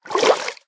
swim3.ogg